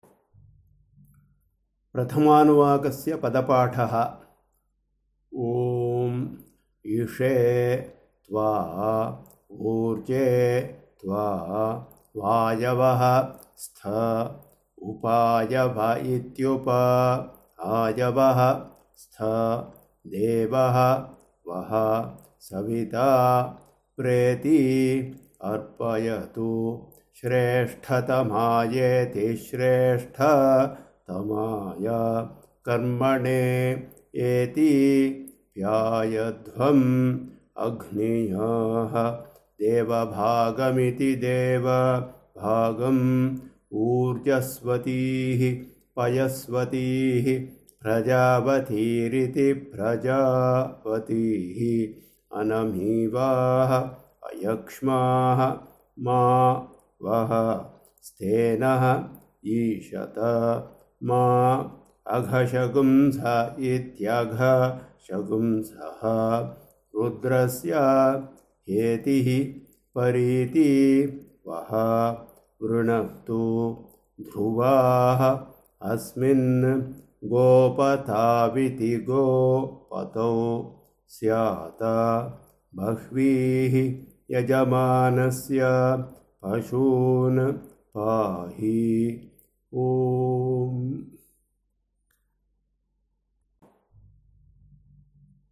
I have attached here a tradition of chanting the above: both the Saṃhitā and the Pada-Pāṭha in two different traditions.
Pada-Pāṭha
anu1-padapatha.mp3